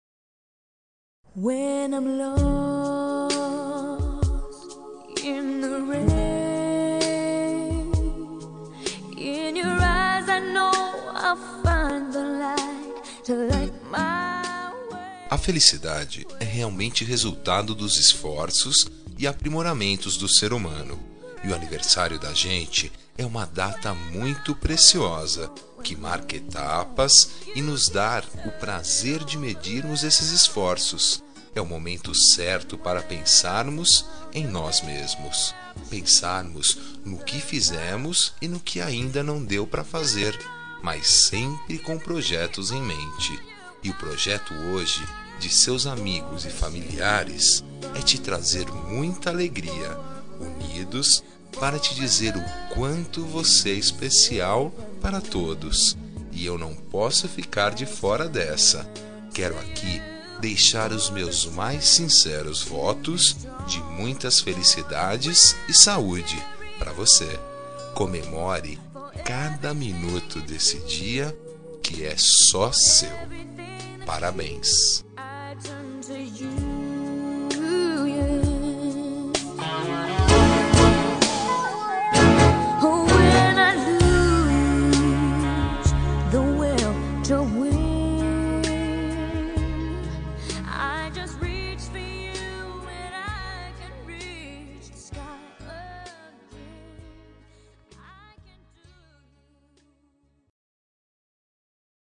Aniversário de Pessoa Especial – Voz Masculina – Cód: 19090